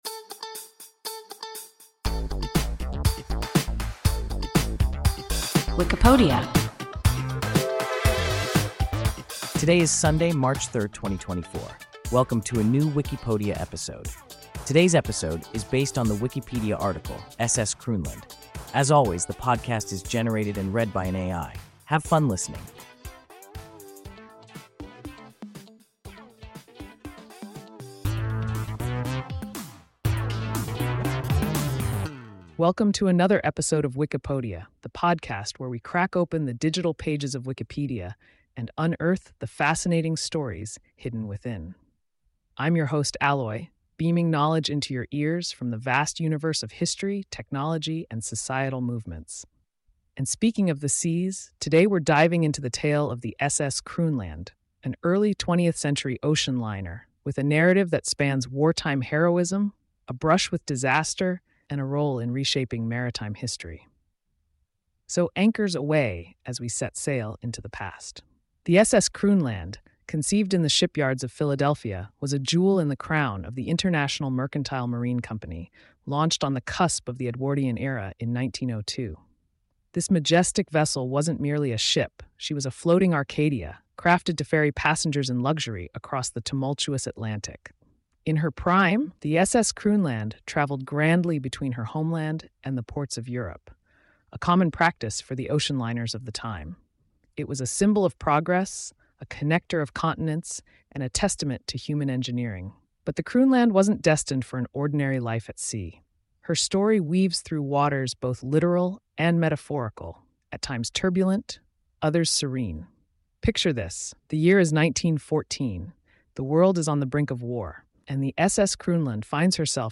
SS Kroonland – WIKIPODIA – ein KI Podcast